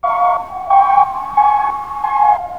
Index of /90_sSampleCDs/USB Soundscan vol.02 - Underground Hip Hop [AKAI] 1CD/Partition E/05-STRINGS
STRINGS 2P-L.wav